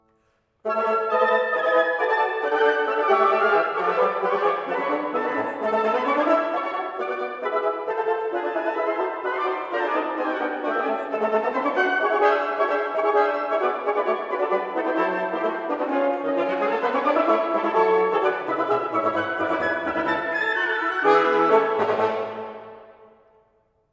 Quintet for wind, Op.43
08-Quintet-for-Flute-Oboe-Clarinet-Horn-and-Bassoon-Op.-43_-Variation-IV-Più-vivo.m4a